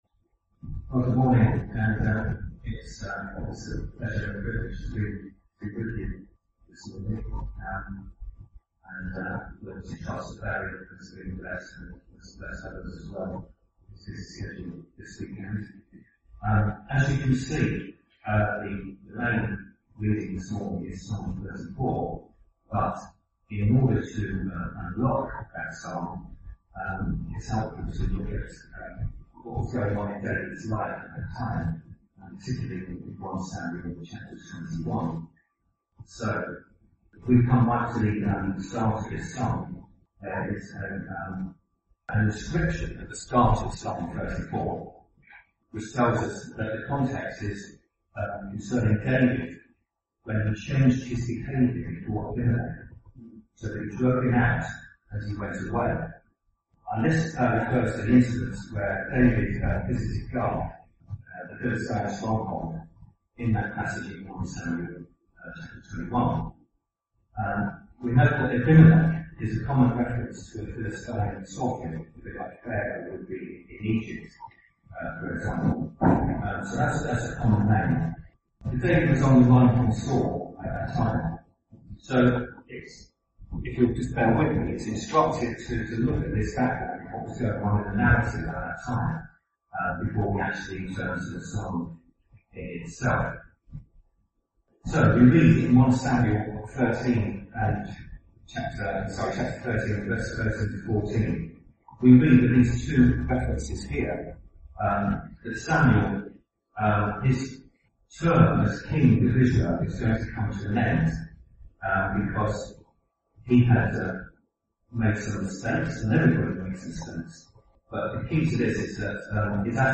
Genre: Bible Teaching.